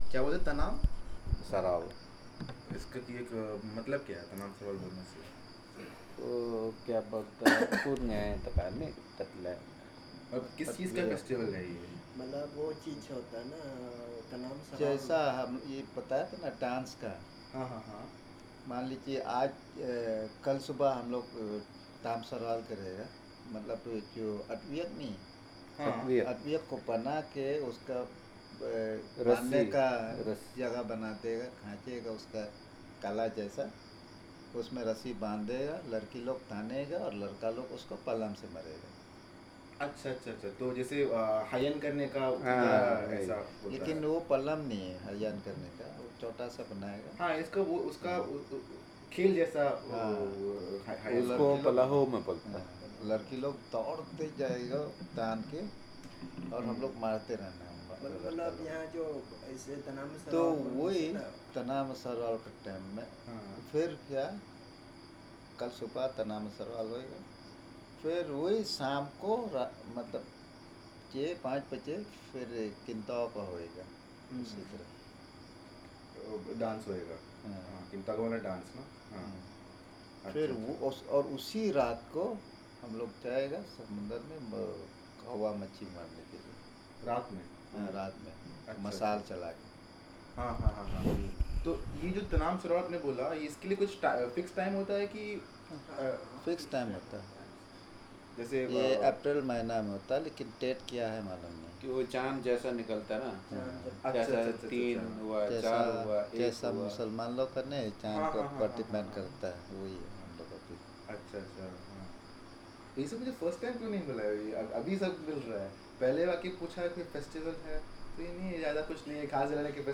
Discussion on the various festivals of Teressa Island